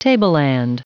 Prononciation du mot tableland en anglais (fichier audio)
Prononciation du mot : tableland